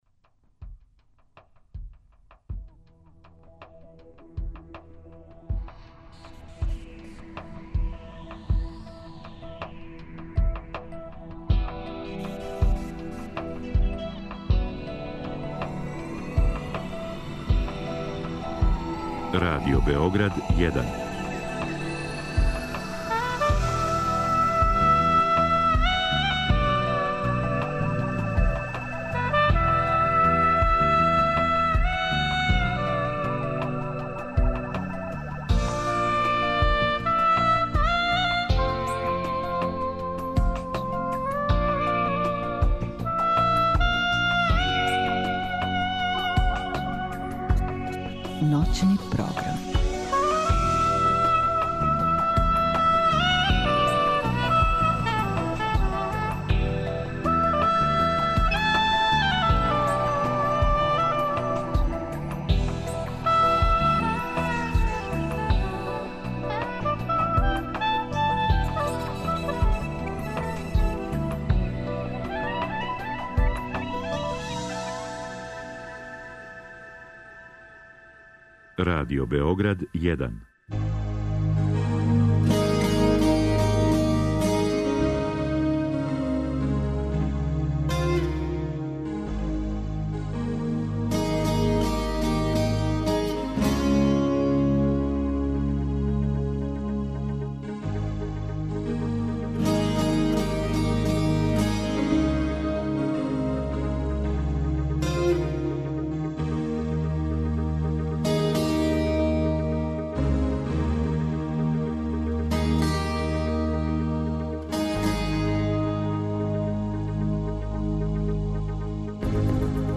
Разговор и добра музика требало би да кроз ову емисију и сами постану грађа за снове.
У другом делу емисије, од 02,05 до 04,00 часова, слушаћемо тонски запис сећања и размишљања Милоша Црњанског. Писац овде говори о свом животу, својим делима, о књижевним узорима и пријатељима, о вези између прошлости и садашњости.